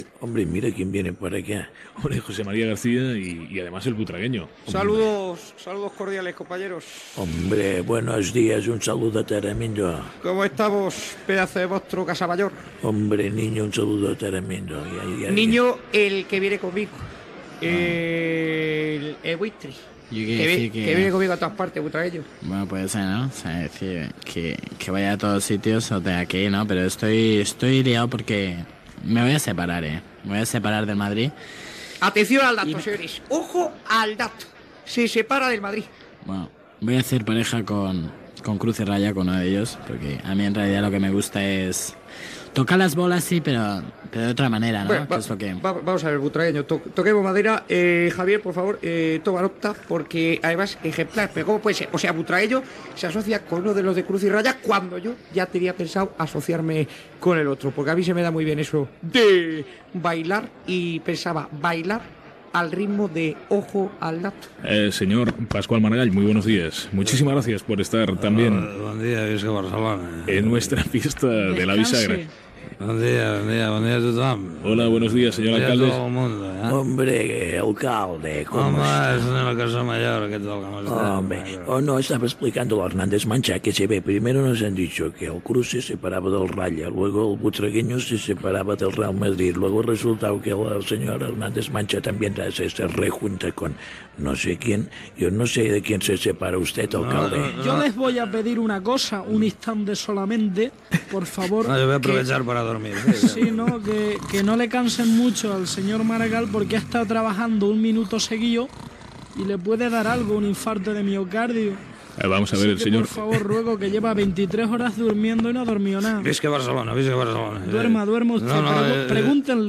Imitacions del periodista José María García, el futbolista Emilio Butragueño i els polítics Pasqual Maragall i Antonio Hernández Mancha
Entreteniment